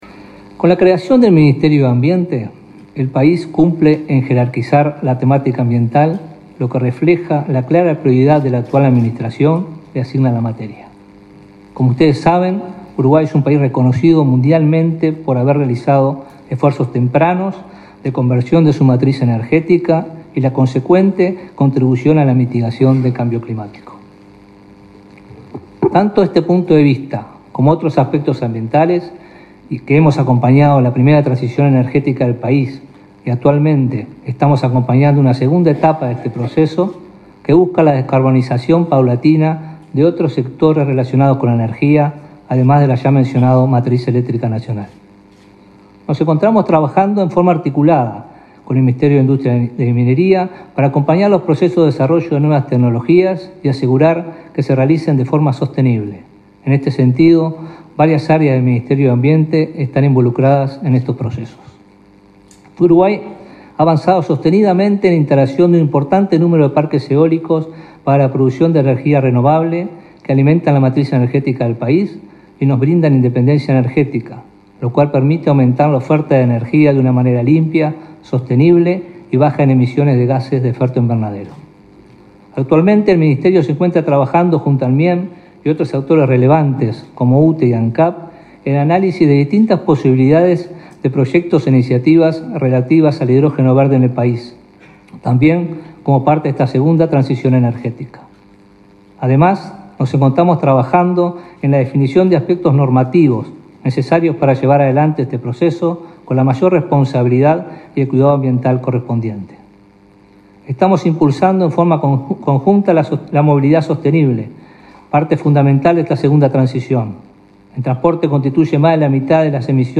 Disertación del ministro de Ambiente, Robert Bouvier
Disertación del ministro de Ambiente, Robert Bouvier 26/04/2023 Compartir Facebook X Copiar enlace WhatsApp LinkedIn El ministro de Ambiente, Robert Bouvier, disertó en una conferencia sobre el desafío de las transiciones energéticas, organizada por Ancap y Arpel y realizada este miércoles 26 en Montevideo.